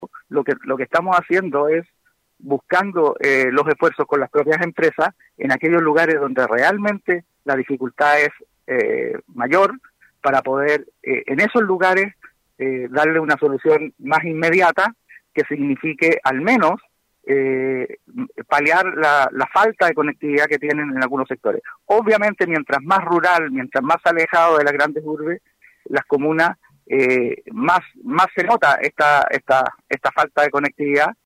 Consultado por la situación, el seremi de Transportes y Telecomunicaciones, Jaime Aravena, acusó que es un problema de infraestructura tecnológica por la que pasa el país con la alta demanda durante la contingencia sanitaria y que ha dejado en evidencia las dificultades acentuadas en zonas rurales y remotas.